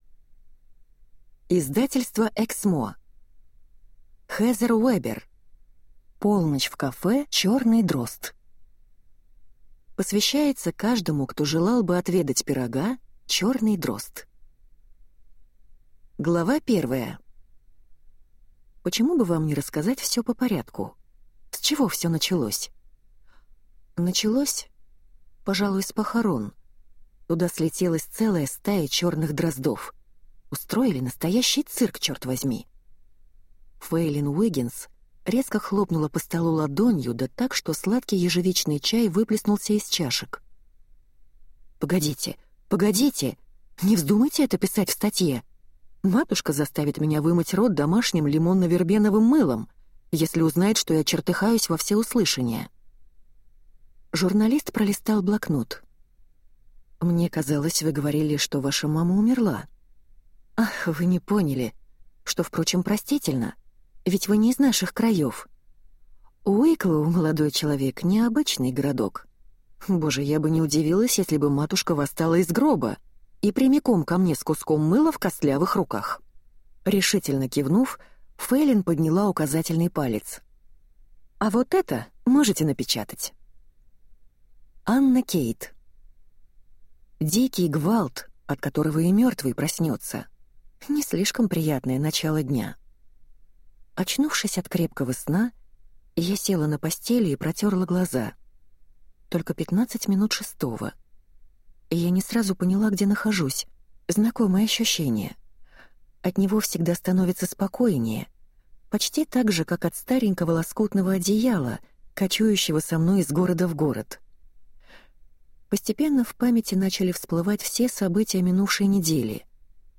Аудиокнига Полночь в кафе «Черный дрозд» | Библиотека аудиокниг